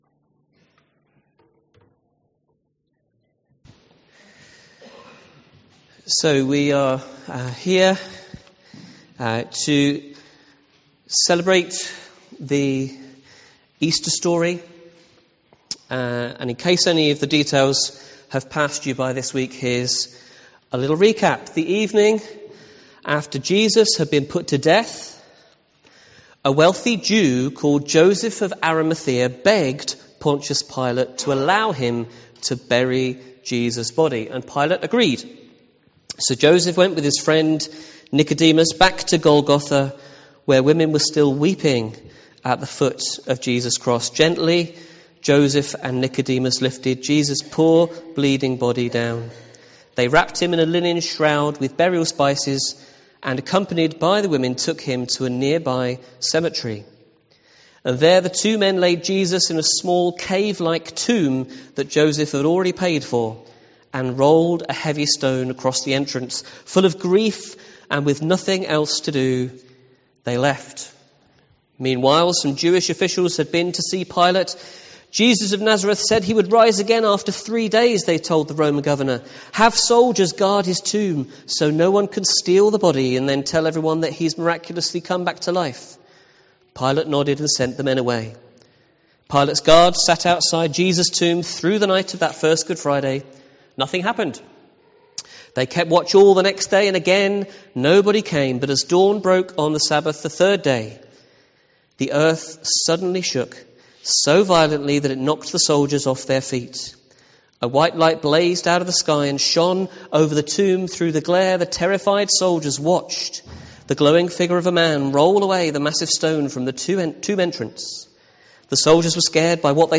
An audio file of the service is now available to listen to.